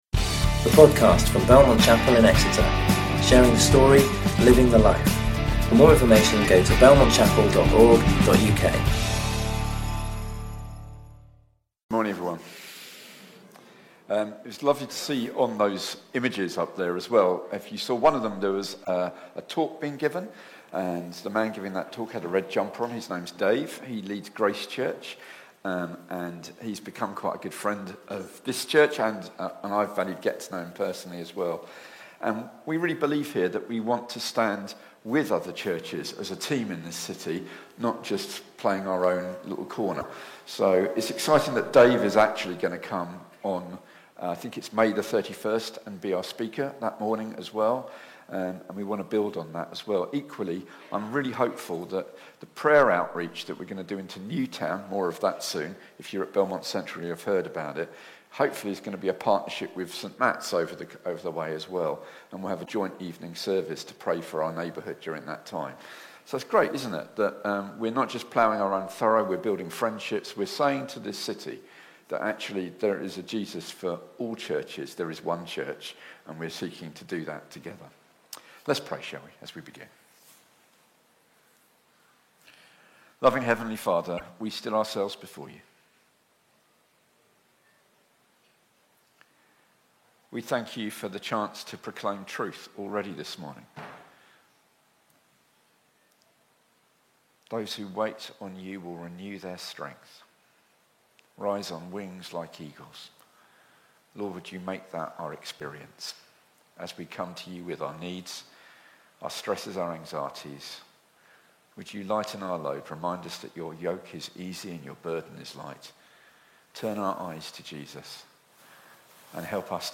You can listen to or download sermons from Belmont Chapel.